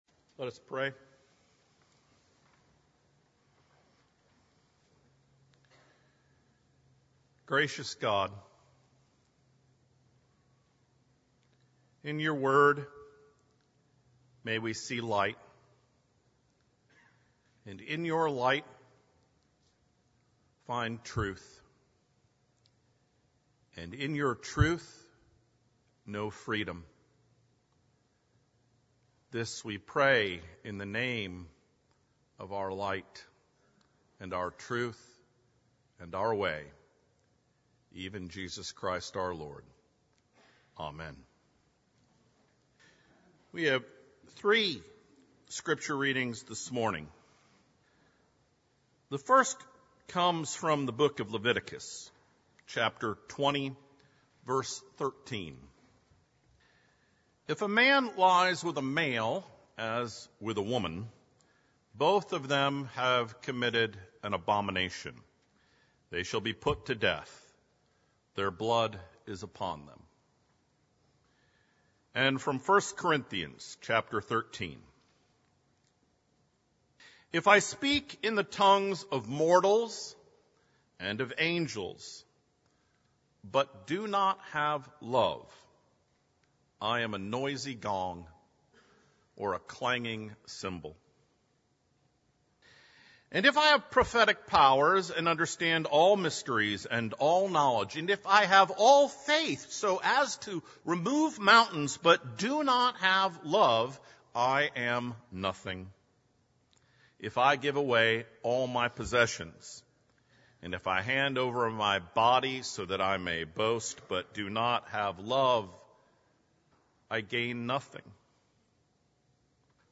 Third Sunday after the Epiphany | Sermons at Fifth Avenue Presbyterian Church